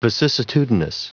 Prononciation du mot vicissitudinous en anglais (fichier audio)
Prononciation du mot : vicissitudinous
vicissitudinous.wav